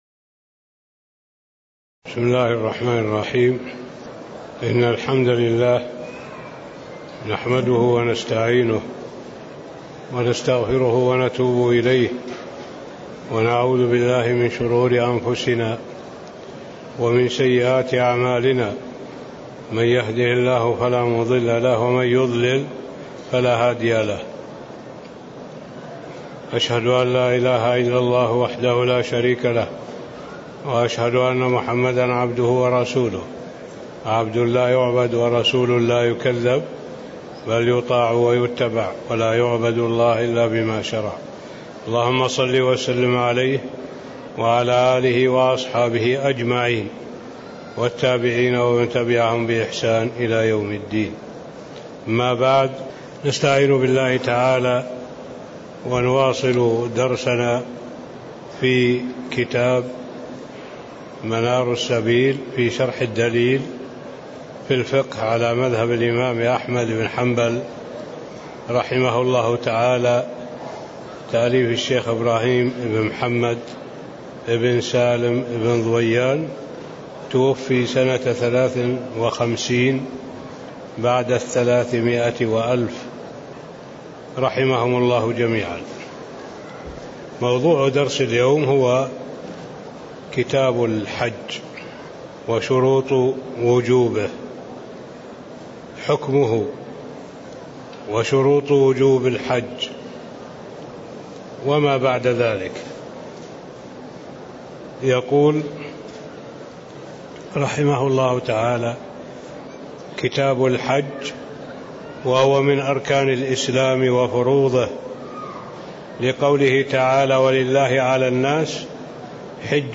تاريخ النشر ١٦ شوال ١٤٣٦ هـ المكان: المسجد النبوي الشيخ